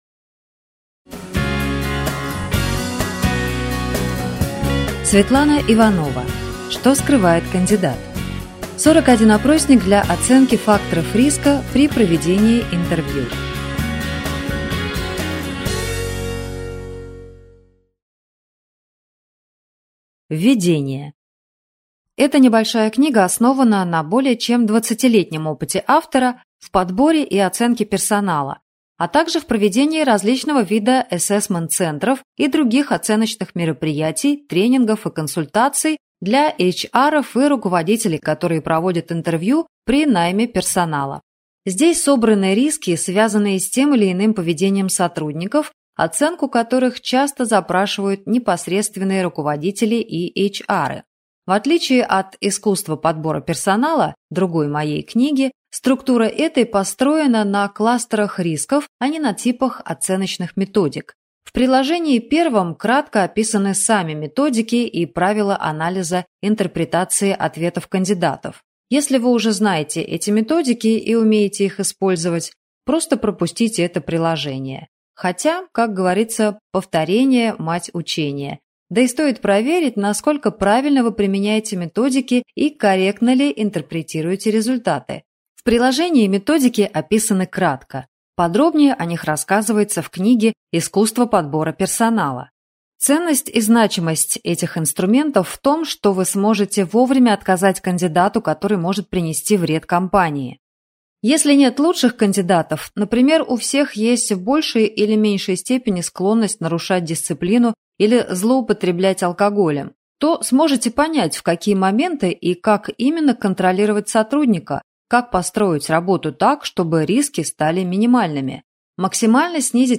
Аудиокнига Что скрывает кандидат?